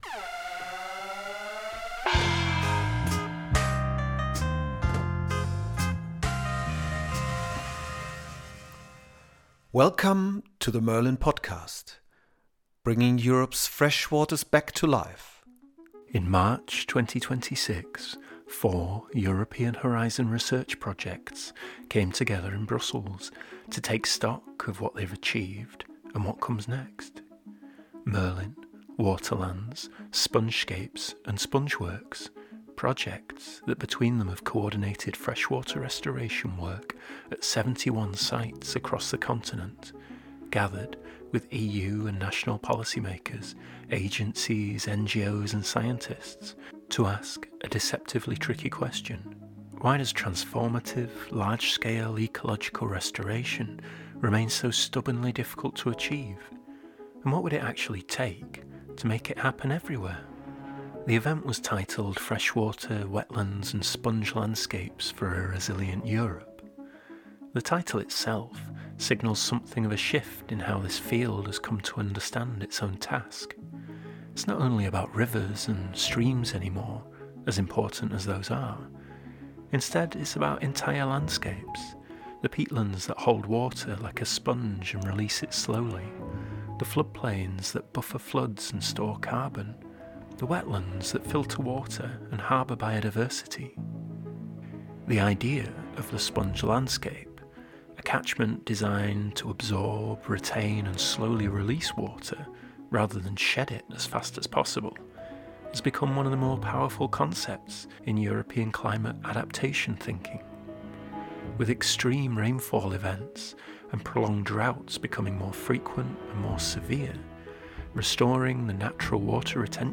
In this episode of the MERLIN podcast we tune into that conversation, ranging across governance, finance, agricultural and environmental policy, and the challenge of building the kind of shared ecological literacy that makes transformative change possible. It is a candid, reflective and ultimately hopeful discussion, held at a moment when the tools and evidence for restoration have never been better, and the pressure to act has never been greater.